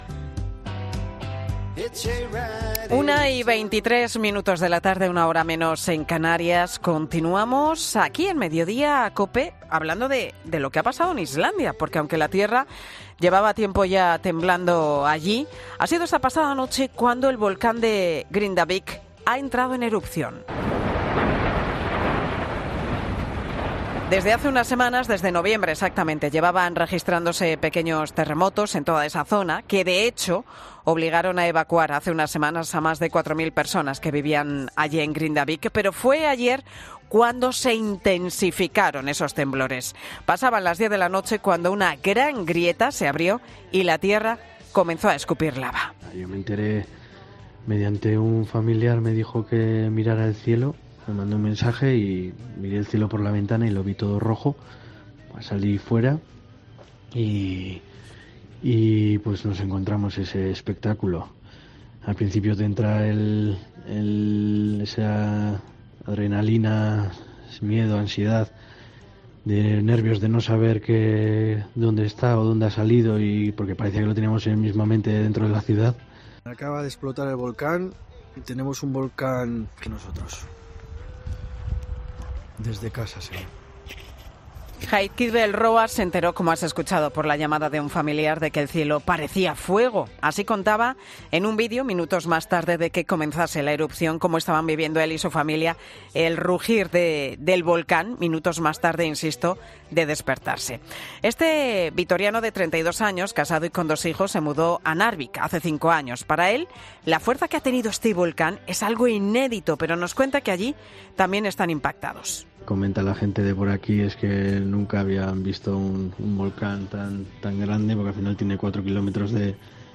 En Mediodía COPE, el testimonio de españoles que viven cerca del volcán Grindavik